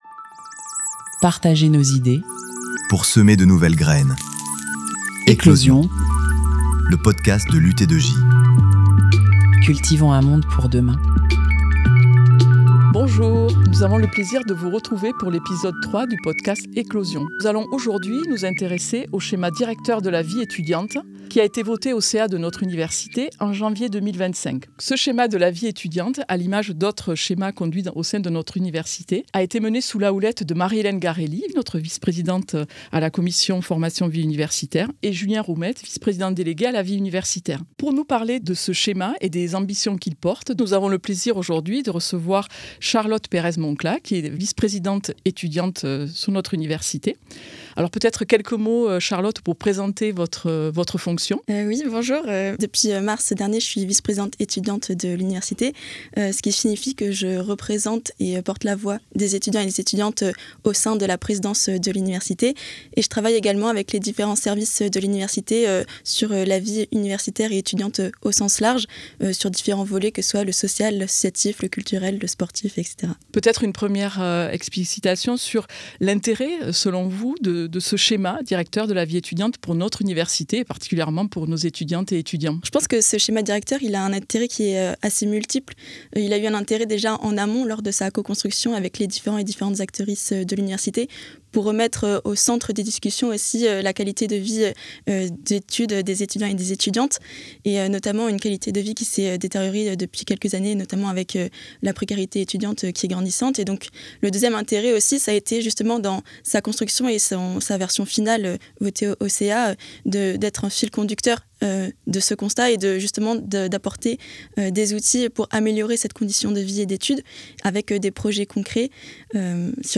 • Interview :